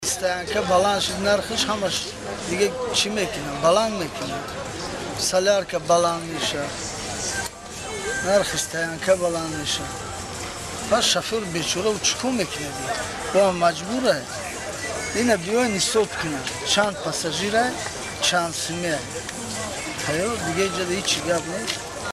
Шарҳи ронандаи масири Хоруғ